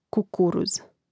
kukùruz kukuruz